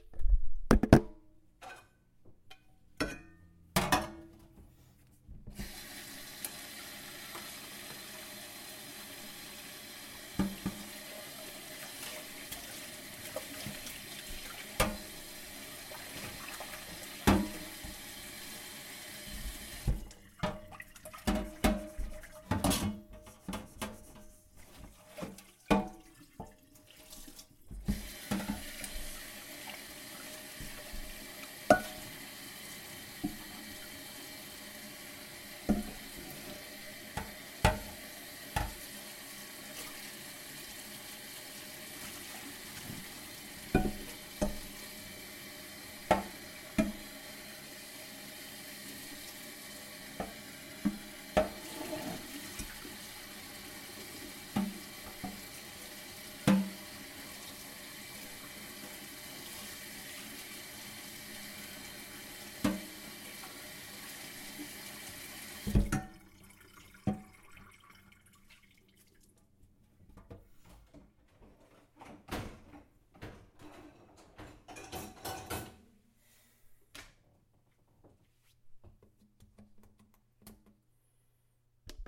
with a Zoom H4n Handy Recorder